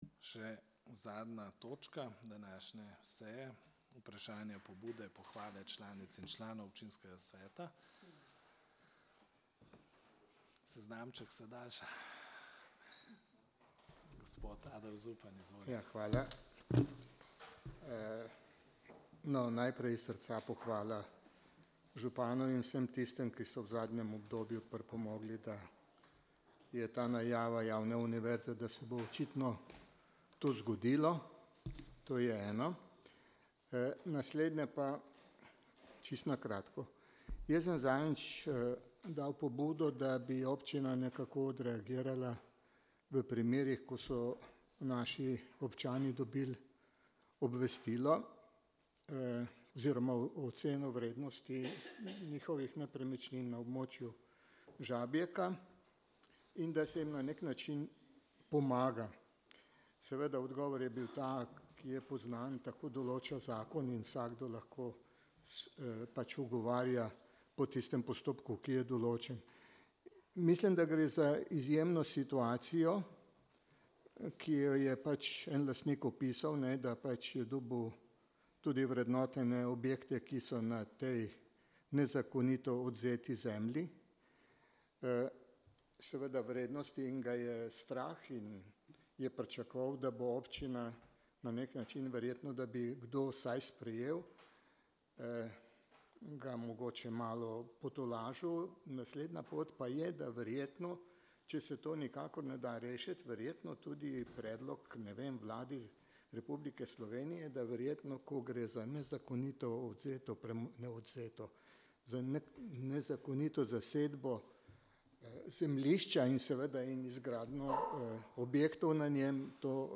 23. seja Občinskega sveta Mestne občine Novo mesto